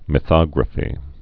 (mĭ-thŏgrə-fē)